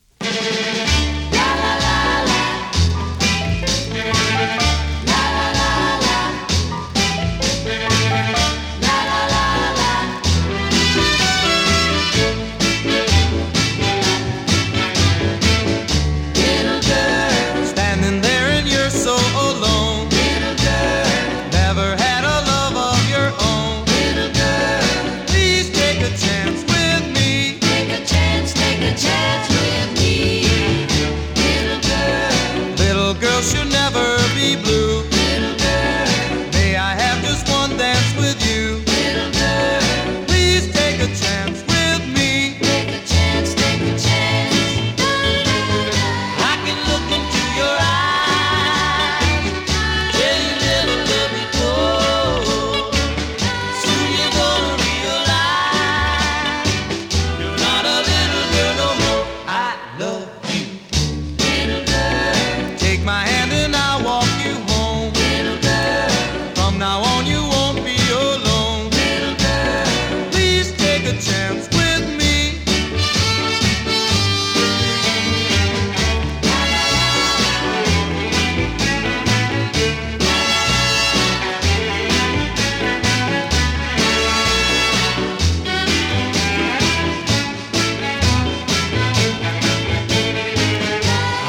類別 R&B、靈魂樂
Great mod R&B double sider !!
試聴 (実際の出品物からの録音です)